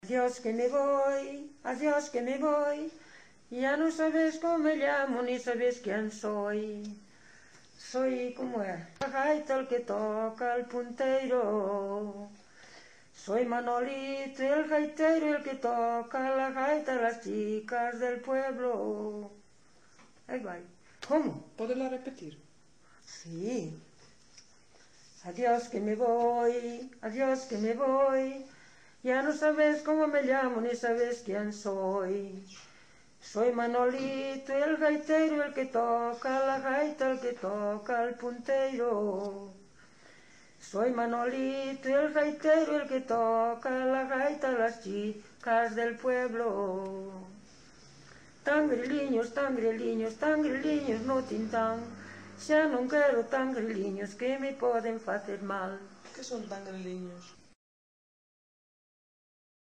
Palabras chave: coplas gaiteiro punteiro tangueriliños
Tipo de rexistro: Musical
Soporte orixinal: Casete
Instrumentación: Voz
Instrumentos: Voz feminina